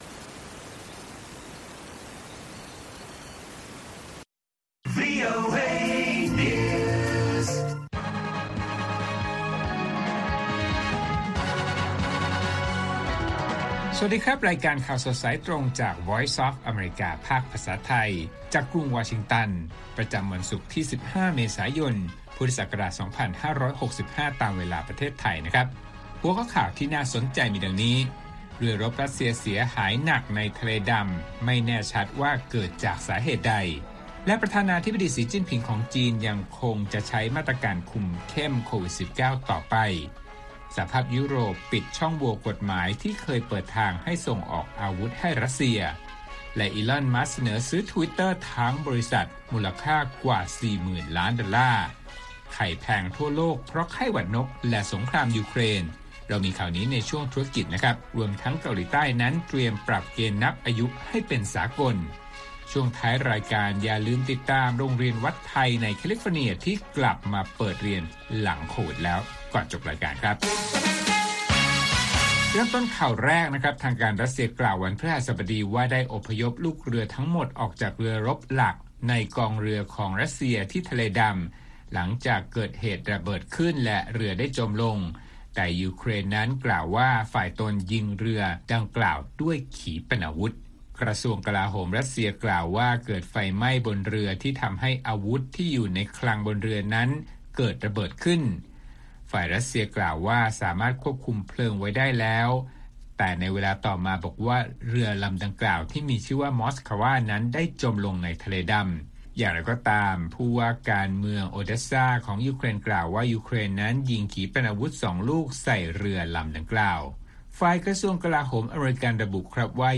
ข่าวสดสายตรงจากวีโอเอ ภาคภาษาไทย ประจำวันศุกร์ที่ 15 เมษายน 2565 ตามเวลาประเทศไทย